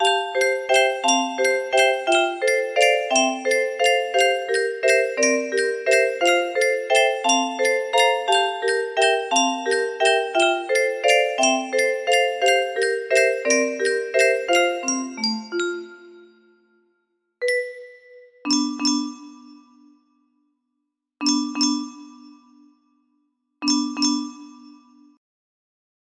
monsterery bells